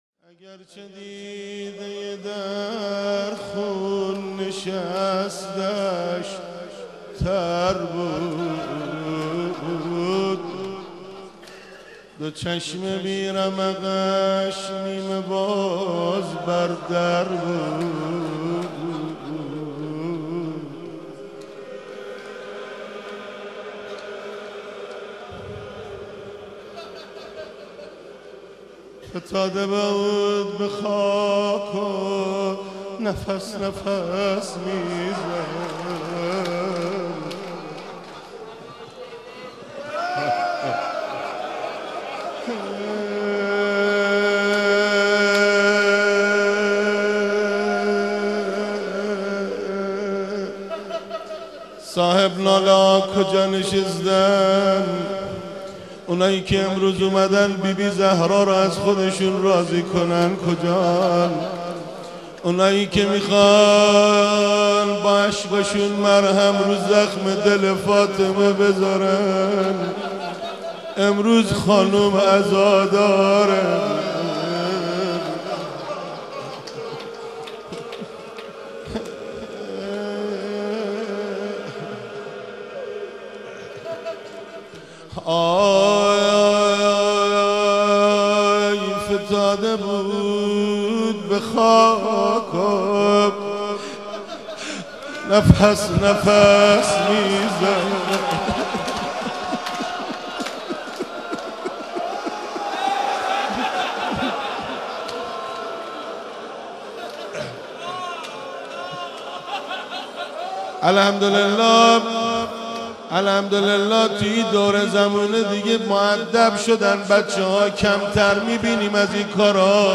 روضه امام جواد(ع)